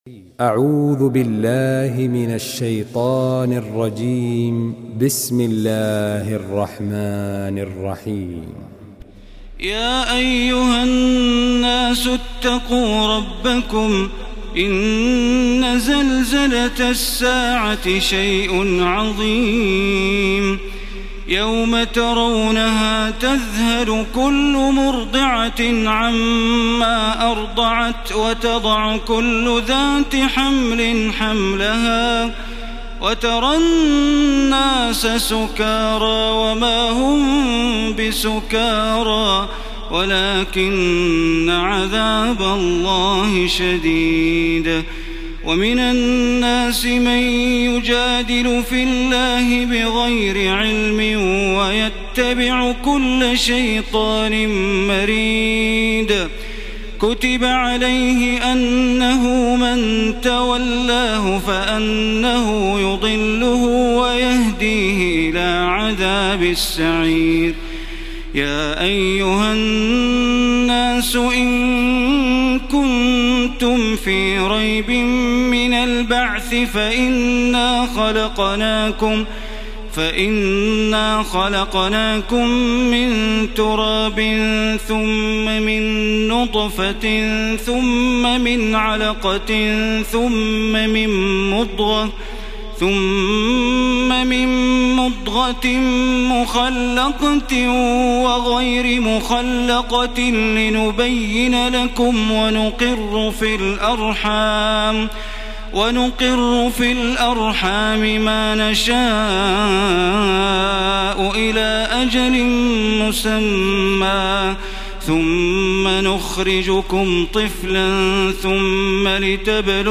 تراويح الليلة السادسة عشر رمضان 1434هـ سورة الحج كاملة Taraweeh 16 st night Ramadan 1434H from Surah Al-Hajj > تراويح الحرم المكي عام 1434 🕋 > التراويح - تلاوات الحرمين